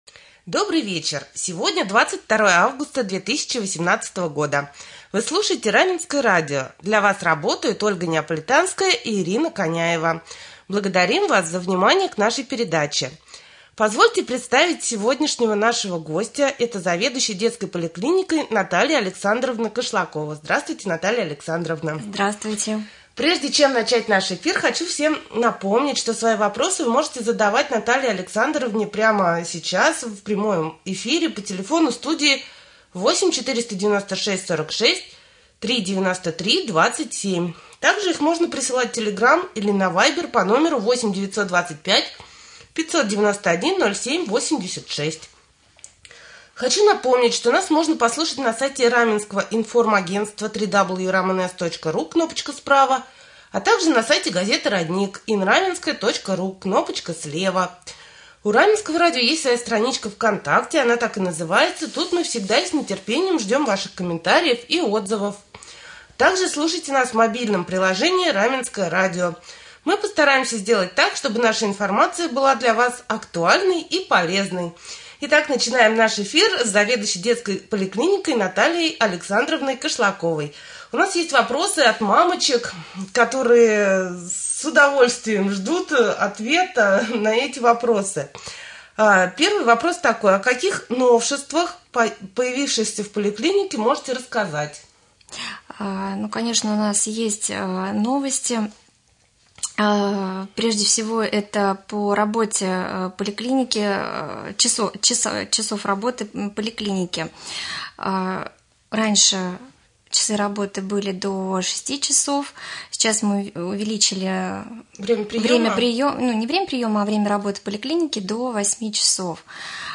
2.Прямой эфир.